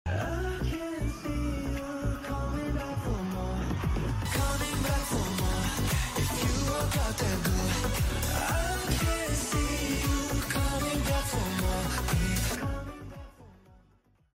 the quality sucks but the song is beautiful🫠